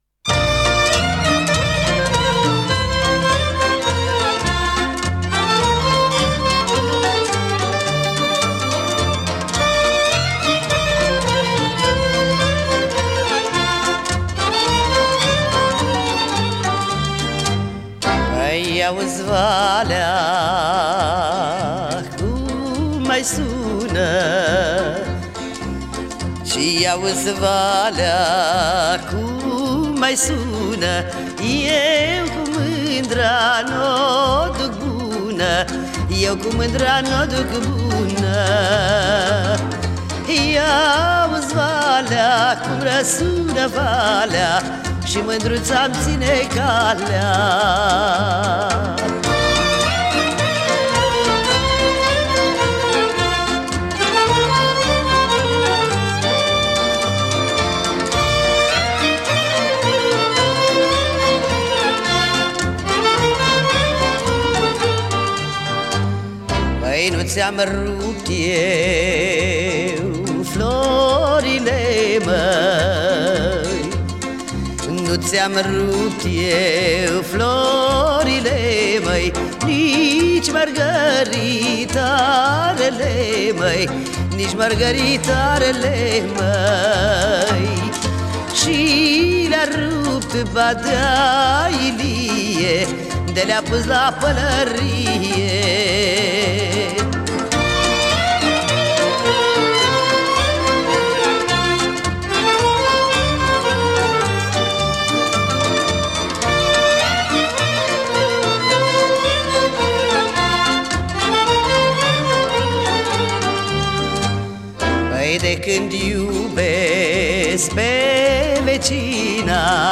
folclor românesc
interpretată de IOANA RADU
I-auzi-valea-cum-suna-Ioana-Radu-folclor-romanesc.mp3